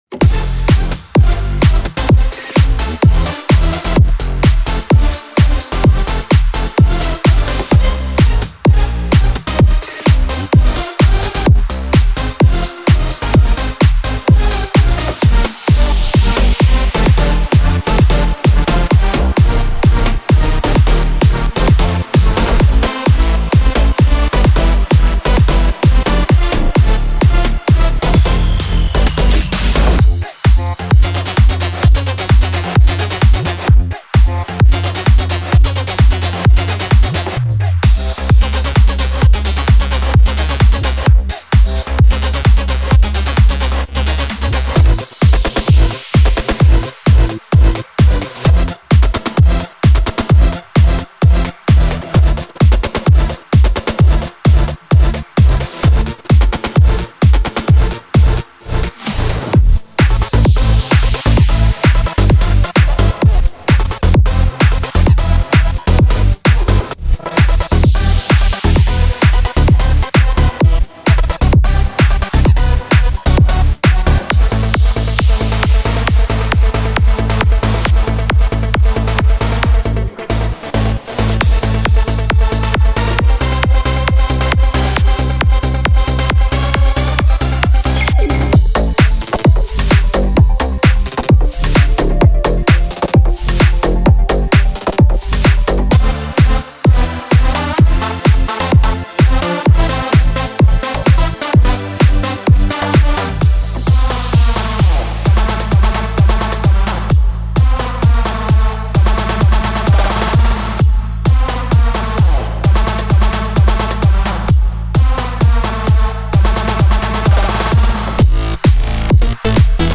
תקשיבו לגימורים של הסאונד.